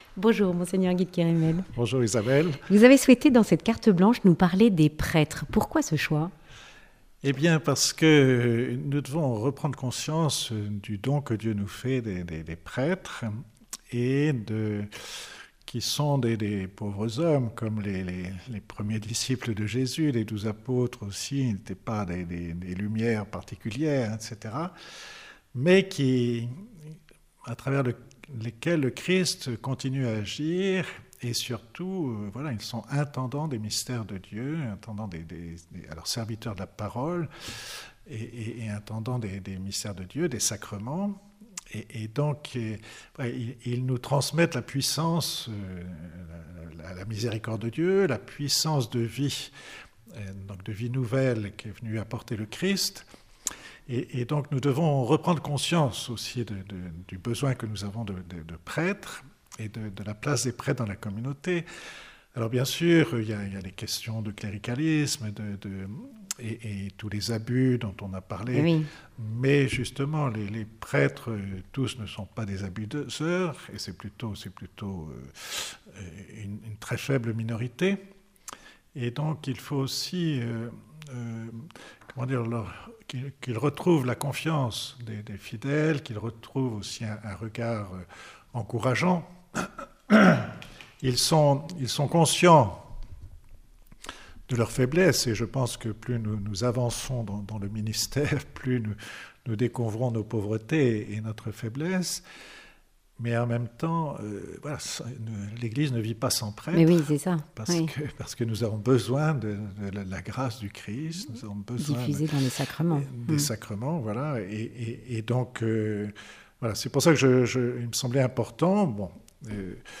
Prêtres, pour l'éternité : Monseigneur Guy de Kerimel, archevêque de Toulouse, propose une réflexion sur la figure du prêtre.